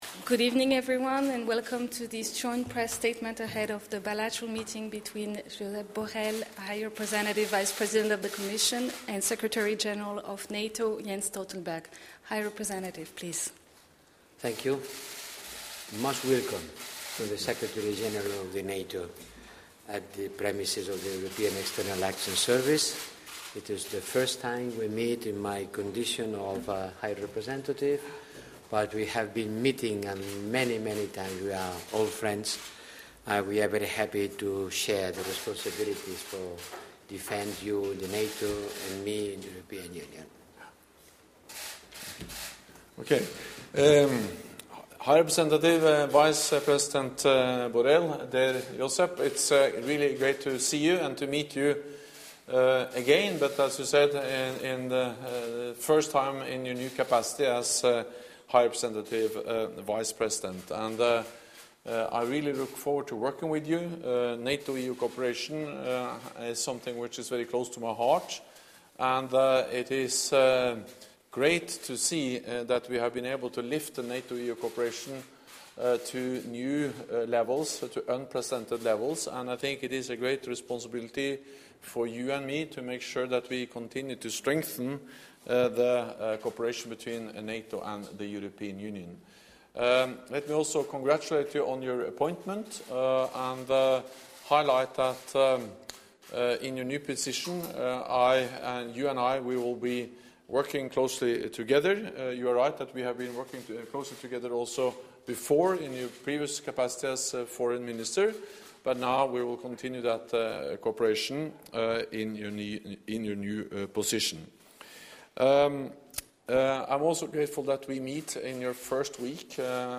NATO Secretary General Jens Stoltenberg met with the new EU High Representative Josep Borrell at the headquarters of the European External Action Service in Brussels on Monday (9 December 2019).